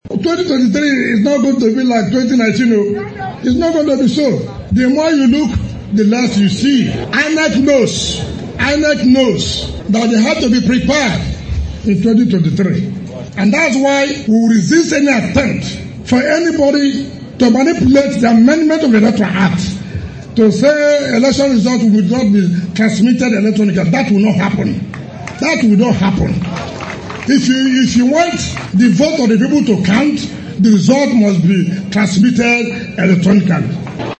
He gave this yesterday during the foundation laying ceremony for the construction of 9200 capacity ultramodern convocation arena of the University of Port Harcourt by the Rivers State government